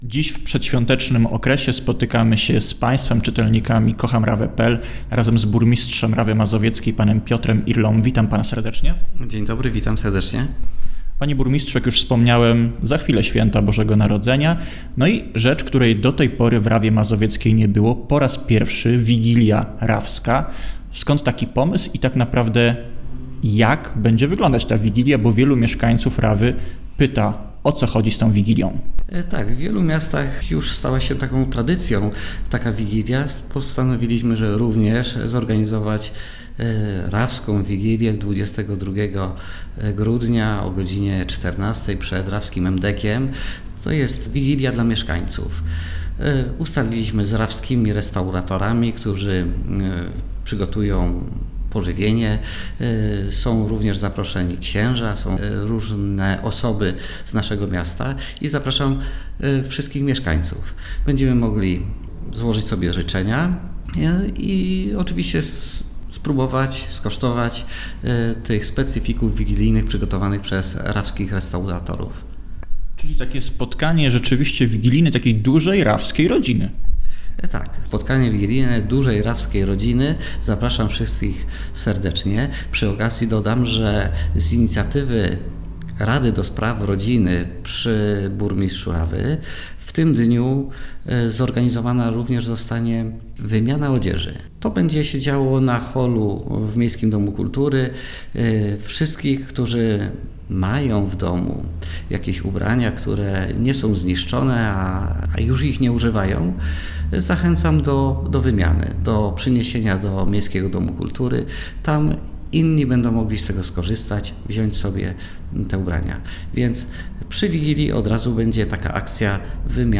Niezwykłe trzy „Koncerty Świąteczne” Maryli Rodowicz w Rawie [zdjęcia]
Życzenia świąteczne mieszkańcom Rawy Mazowieckiej złożył burmistrz Piotr Irla.
Piotr-Irla-wigilia3.mp3